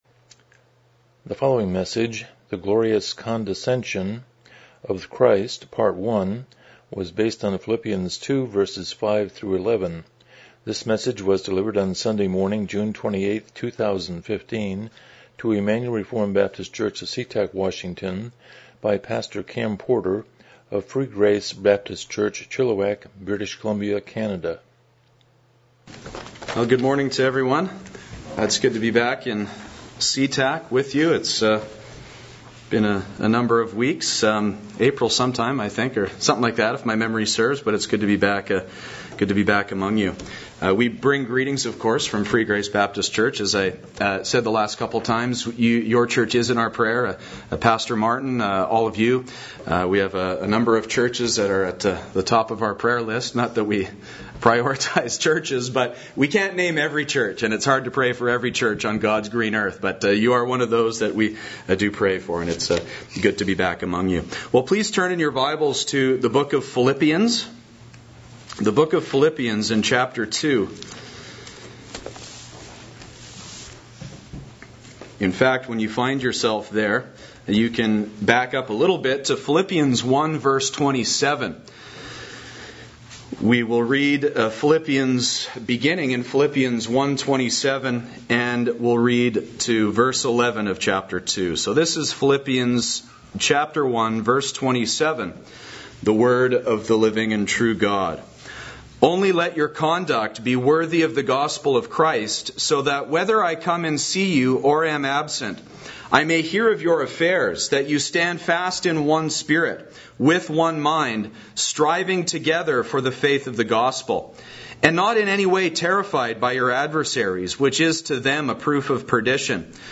Miscellaneous Service Type: Morning Worship « The Importance of the Gospel of Christ The Glorious Condescension of Christ